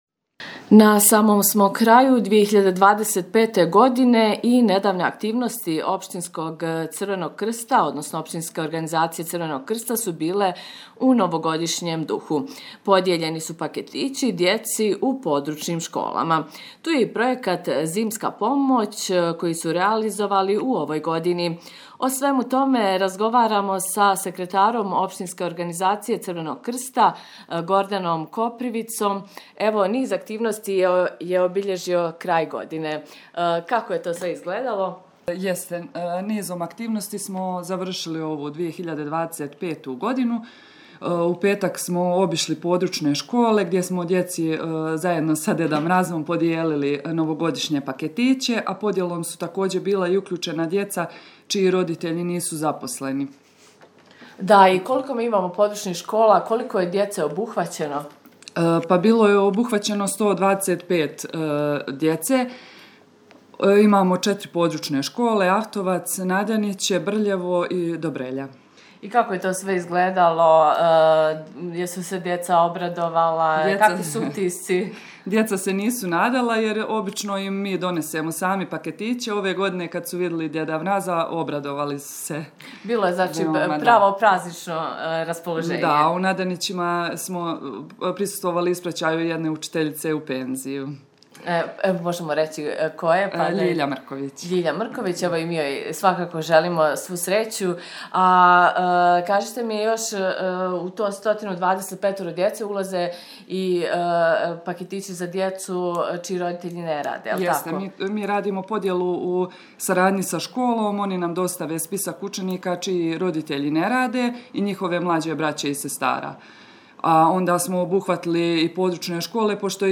Više u razgovoru… https